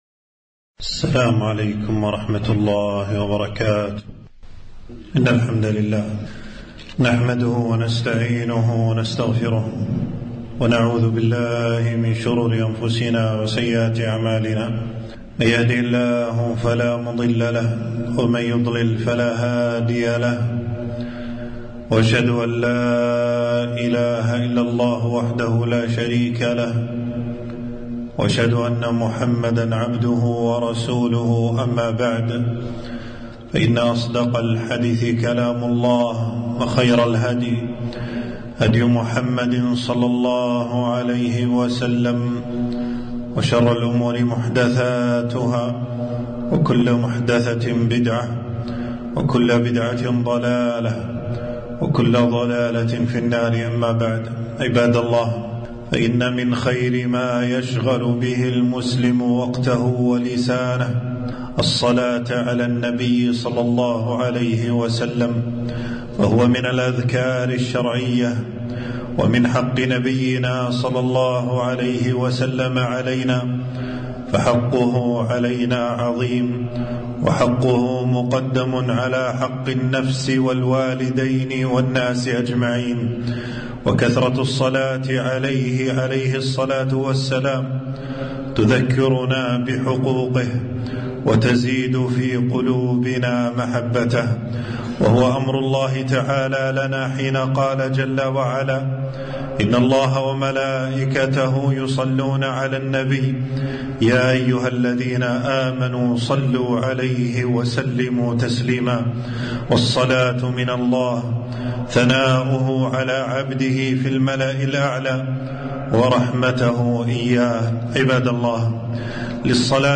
خطبة - تذكير المؤمنين بفضل الصلاة على سيد المرسلين